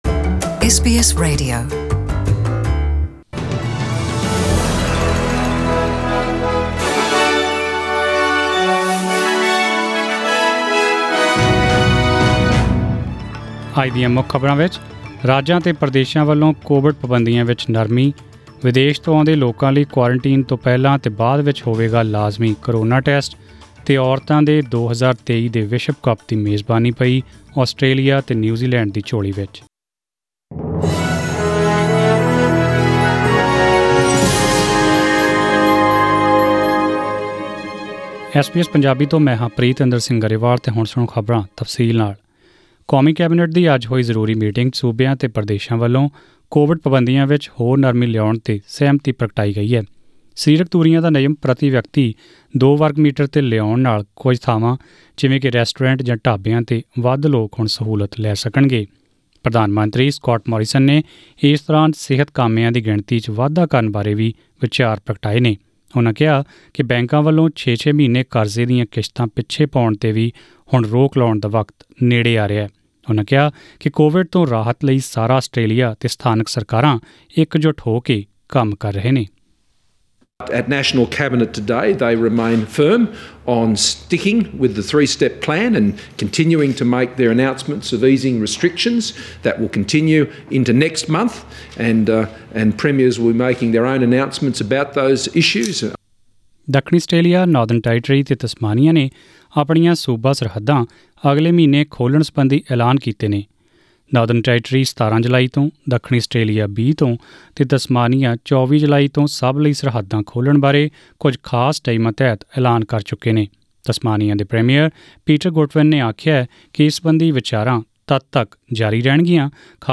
To hear the full news bulletin, click on the audio player above.